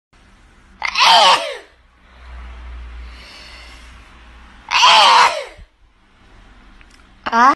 mi-estornudo-dos-tortugas.mp3